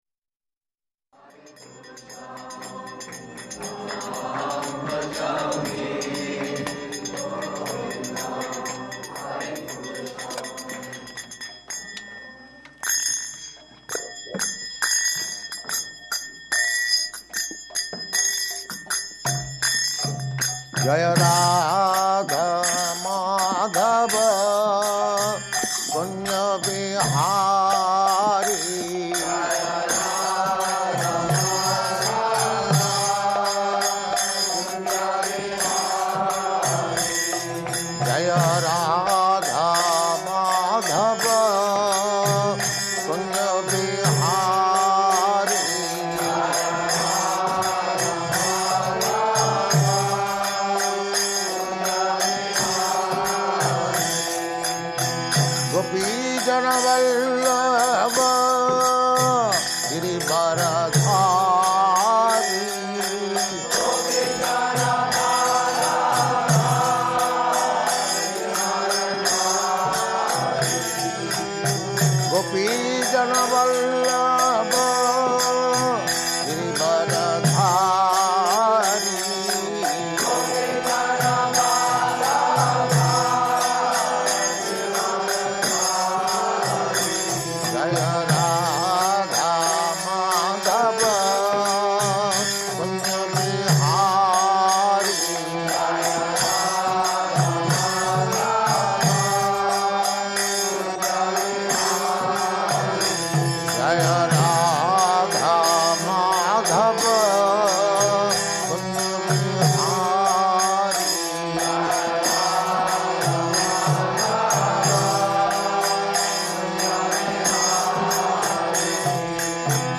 Type: Srimad-Bhagavatam
Location: Los Angeles
[ Govindam prayers playing; sings along]
[leads chanting of verse] [Prabhupāda and devotees repeat] ṣaṣṭham atrer apatyatvaṁ vṛtaḥ prāpto 'nasūyayā ānvīkṣikīm alarkāya prahlādādibhya ūcivān [ SB 1.3.11 ] Prabhupāda: Ladies.